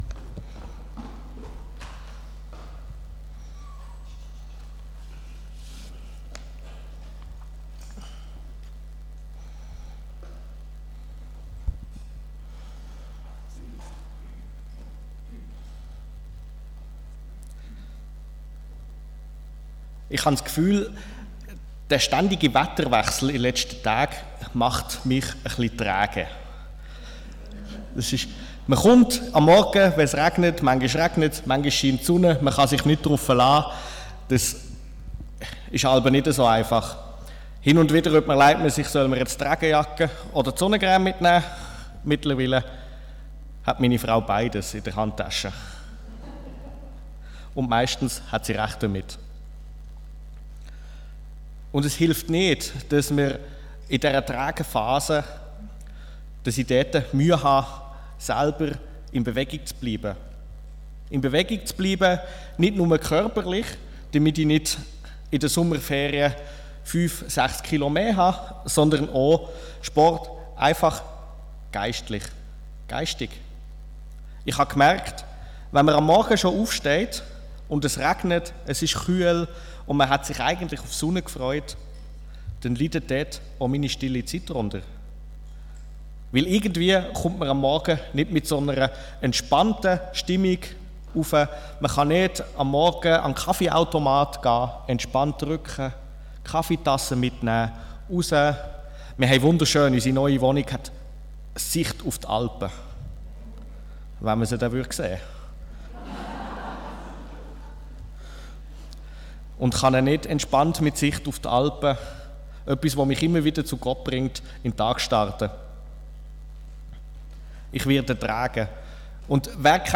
Von Serien: "Diverse Predigten"